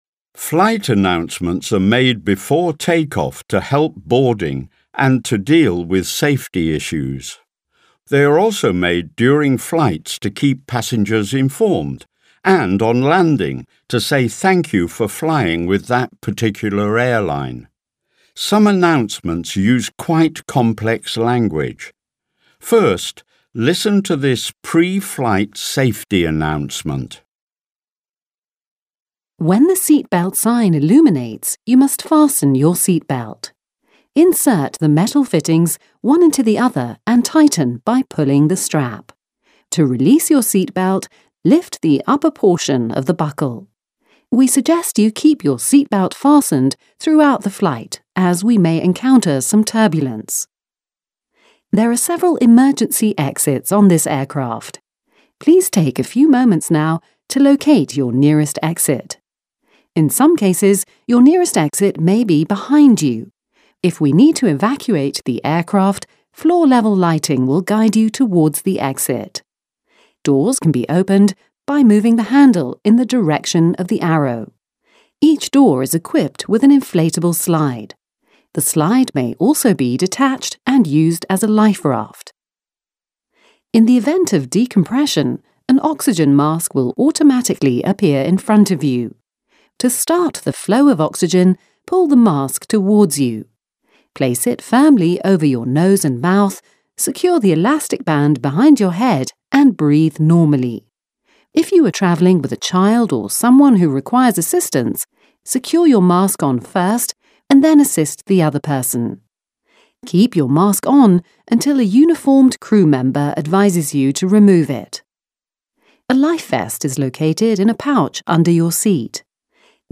Exercise: Vocabulary | ZSD Content Backend
Audio-Übung